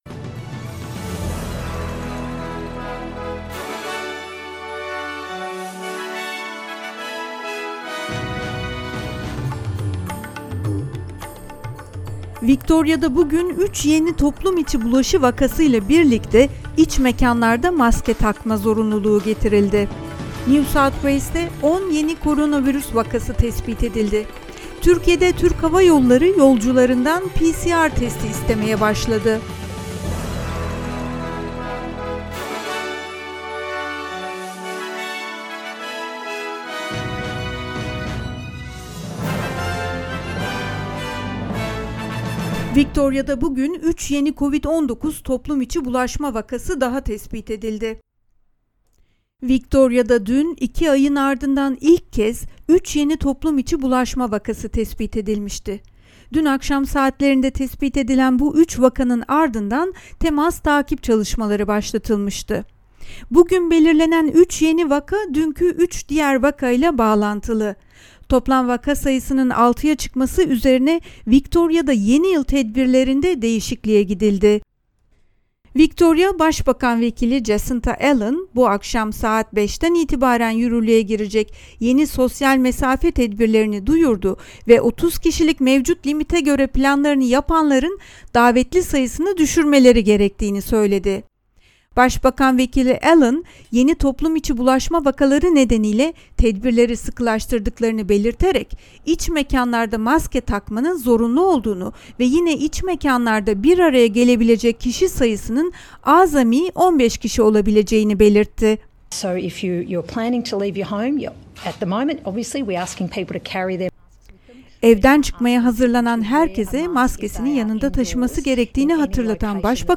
SBS Türkçe Haberler 31 Aralık